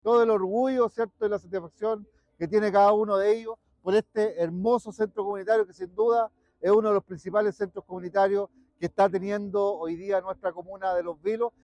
Christian Gross – Alcalde de Los Vilos